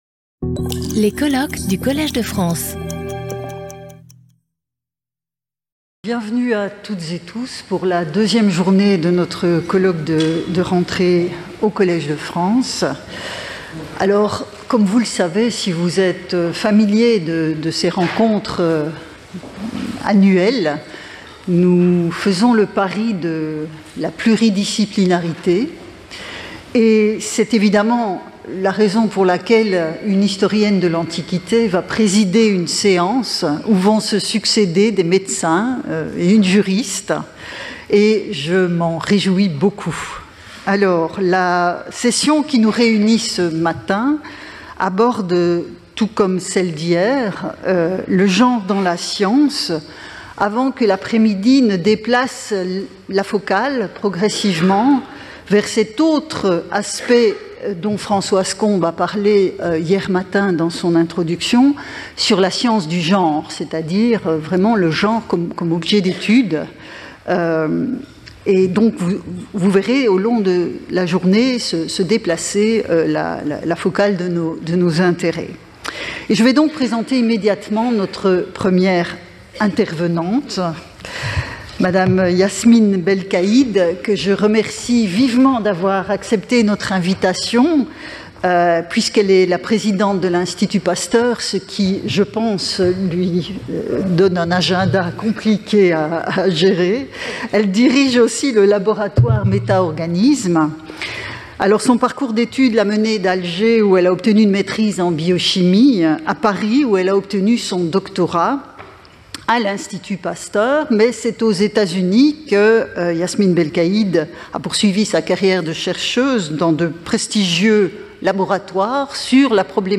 Sauter le player vidéo Youtube Écouter l'audio Télécharger l'audio Lecture audio Séance animée par Vinciane Pirenne-Delforge. Chaque communication de 30 minutes est suivie de 10 minutes de discussion.